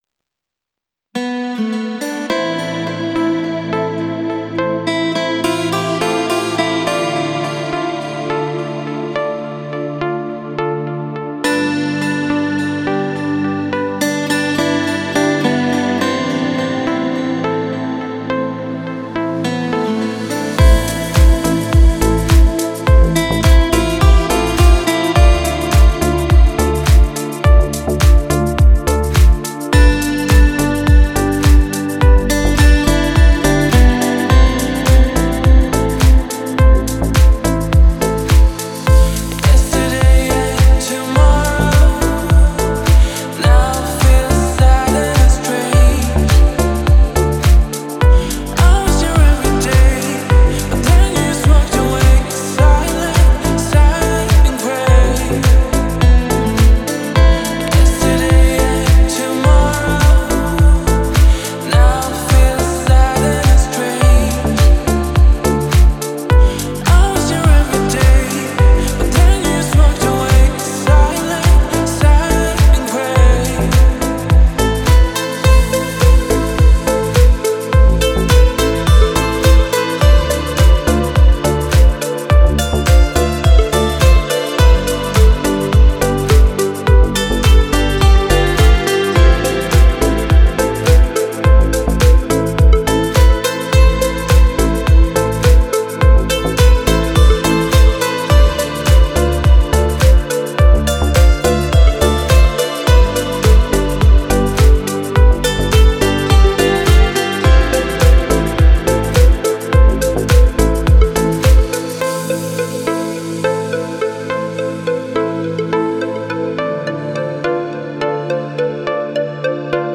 мелодичные треки , красивая музыка без слов